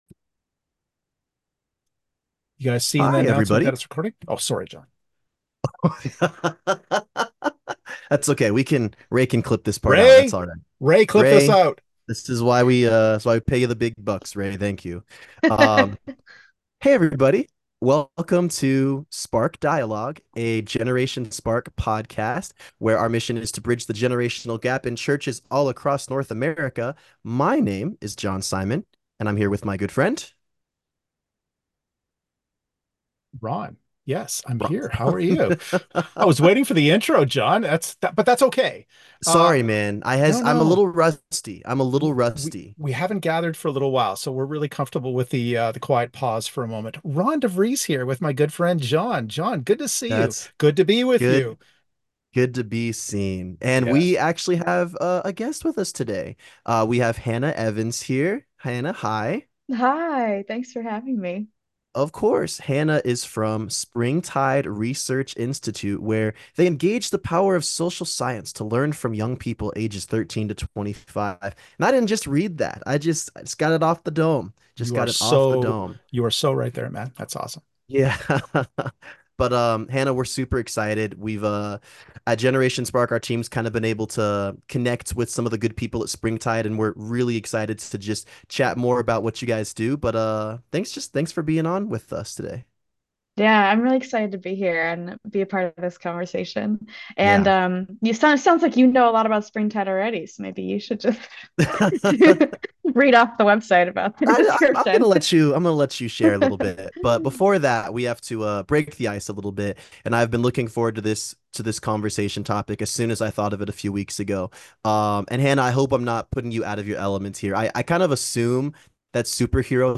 Springtide Research, an Interview